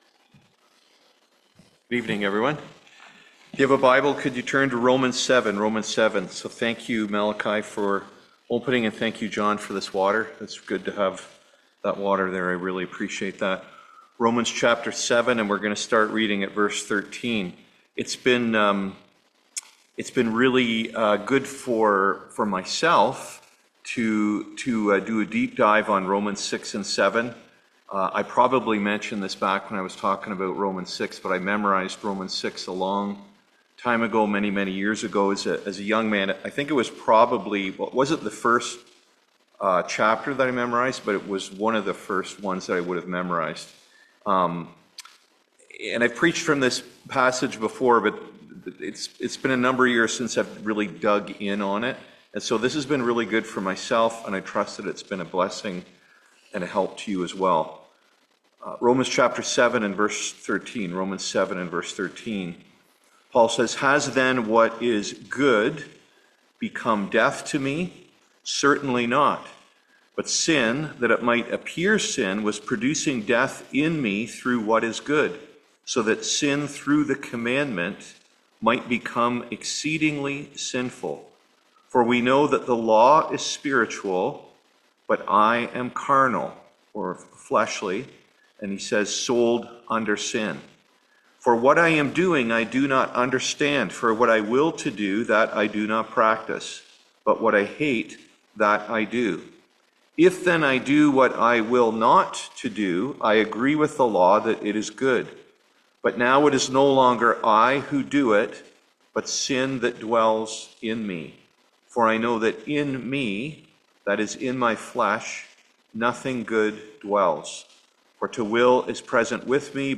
Series: Romans 2025-26 Passage: Romans 7:13-25 Service Type: Seminar